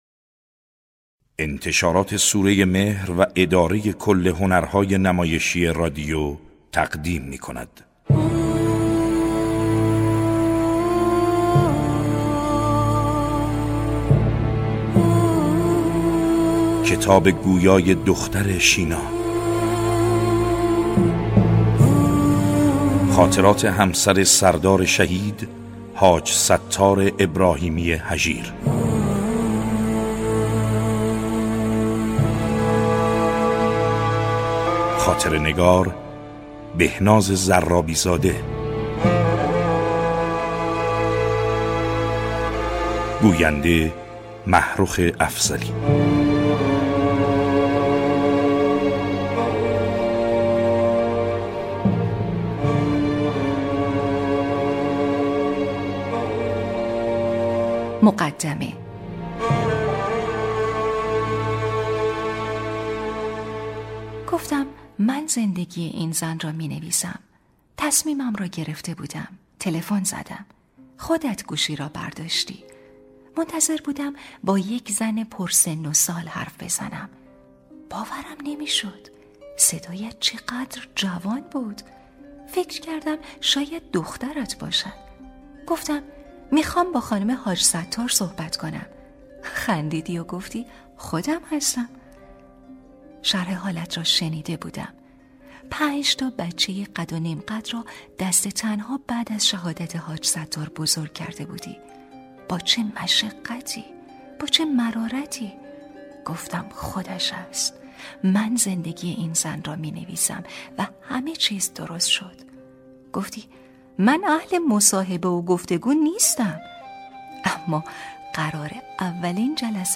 کتاب صوتی | دختر شینا (01)
# روایتگری